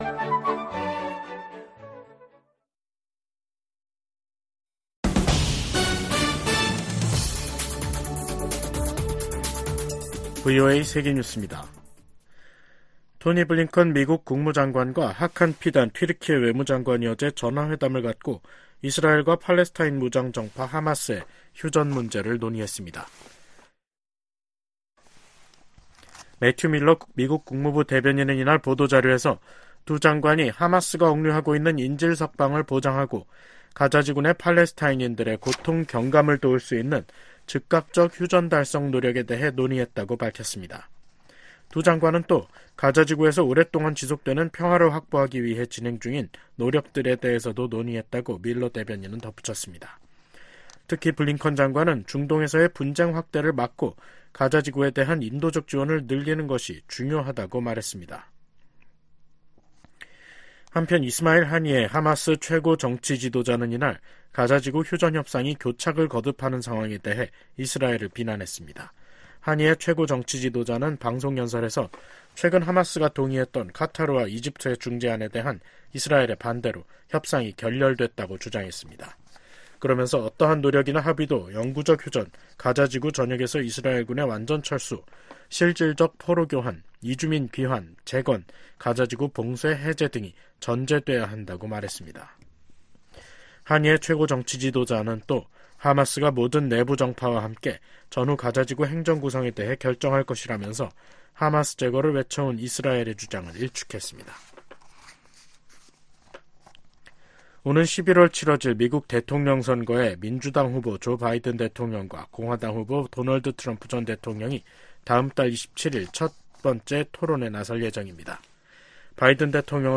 VOA 한국어 간판 뉴스 프로그램 '뉴스 투데이', 2024년 5월 16일 2부 방송입니다. 북한이 28년 연속 미국의 대테러 비협력국으로 지정됐습니다. 중국과 러시아가 정상회담을 갖고 전략적 협력 문제를 논의할 예정인 가운데 미국 정부가 양국에 북한 문제 해결에 나설 것을 촉구했습니다. 미국 전문가들은 블라디미르 푸틴 러시아 대통령의 중국 방문에서 한반도 문제가 주요 의제 중 하나로 논의될 것이라고 전망했습니다.